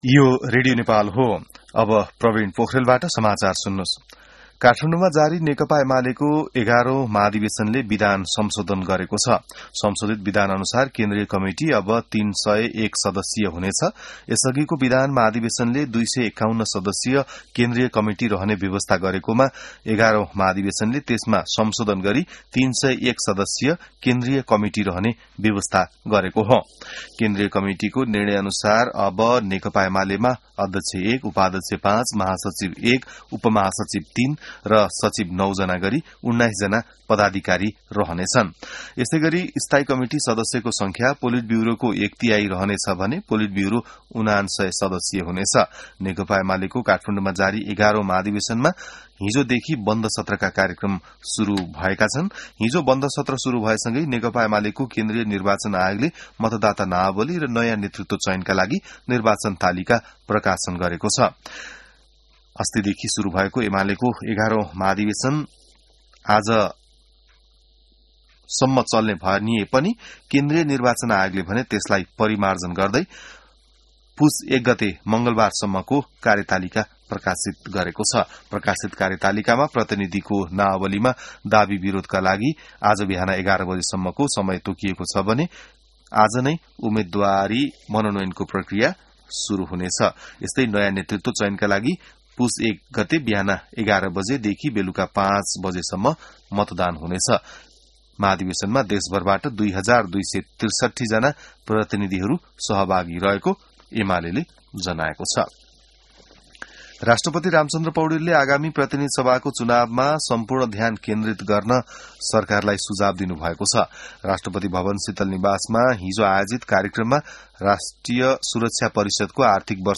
बिहान ६ बजेको नेपाली समाचार : २९ मंसिर , २०८२